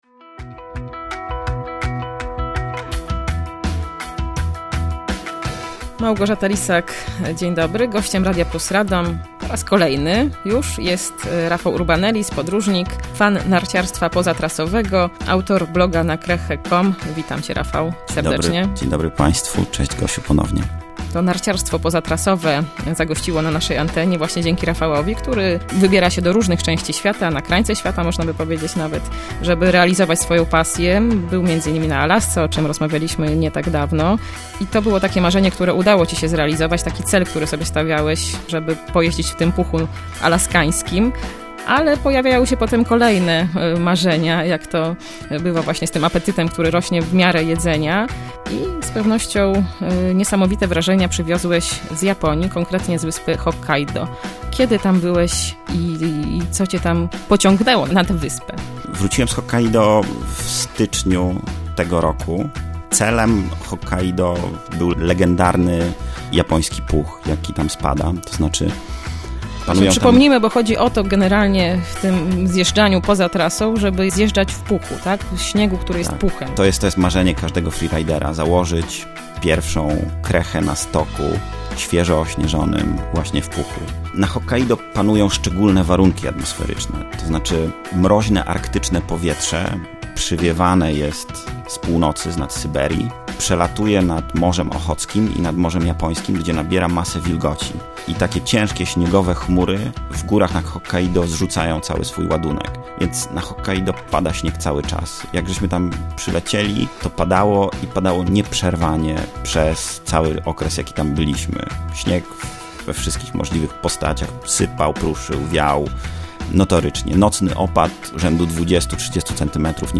🙂 Jeśli nie – posłuchajcie: dziś trzecia odsłona wywiadu dla Radia Plus, tym razem o wyprawie na Hokkaido. Rozmawiamy o legendarnym, japońskim puchu, o sushi i ramen, o treeskiingu, o jeździe ratrakami i skuterami śnieżnymi, o Japończykach, o adrenalinie i endorfinach. Oczywiście cała rozmowa kręci się wkoło nart poza trasą.
wywiad-czesc3.mp3